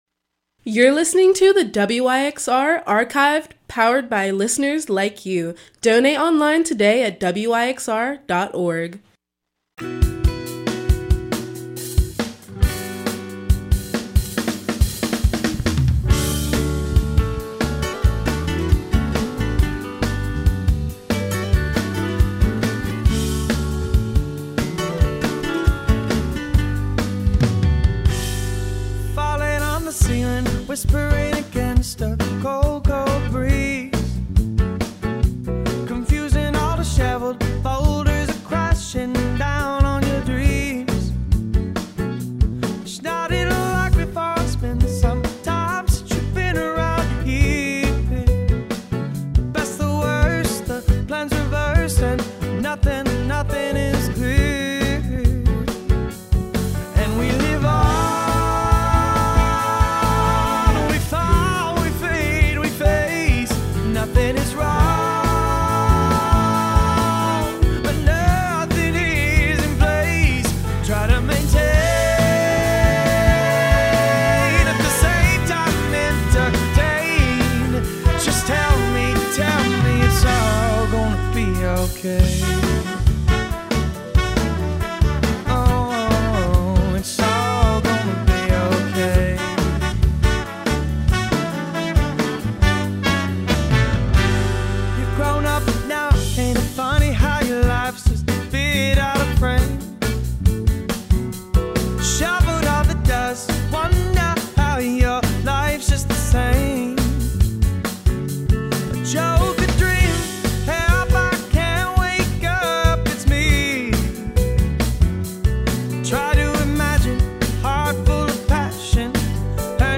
World Jazz Soul